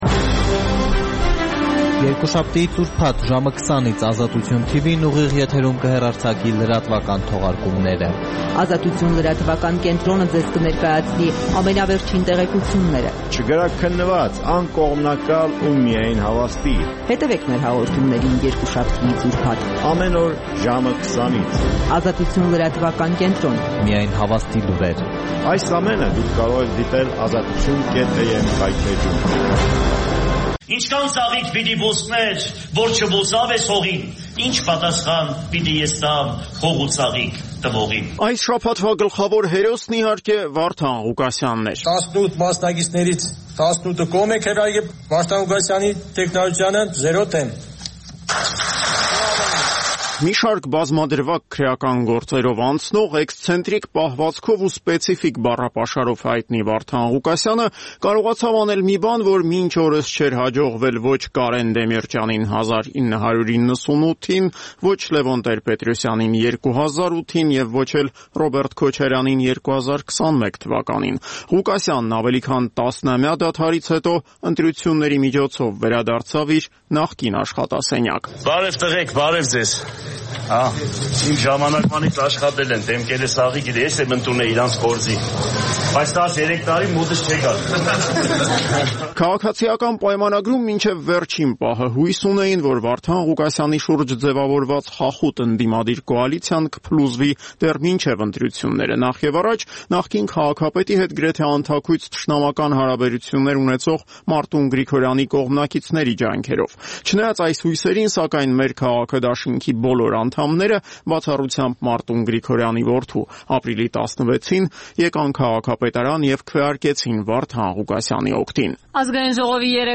«Ազատություն» ռադիոկայանի օրվա հիմնական թողարկումը: Տեղական եւ միջազգային լուրեր, ռեպորտաժներ օրվա կարեւորագույն իրադարձությունների մասին, հարցազրույցներ, մամուլի տեսություն: